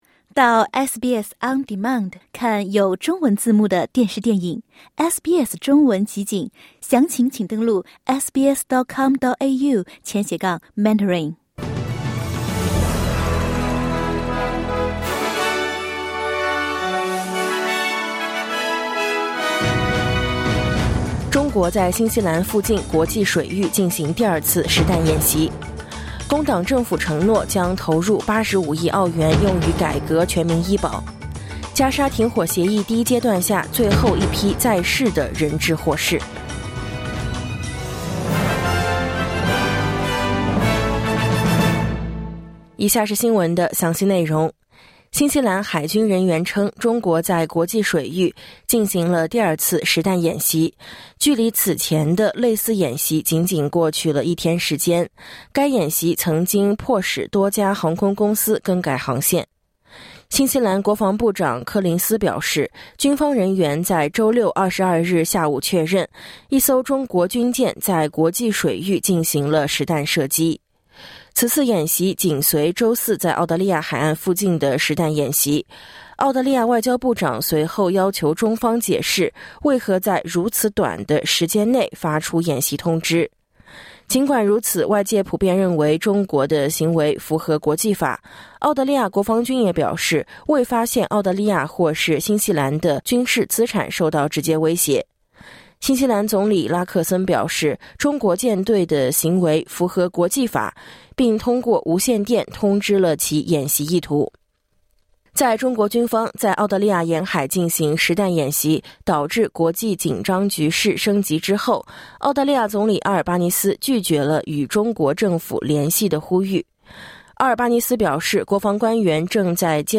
SBS早新闻（2025年2月23日）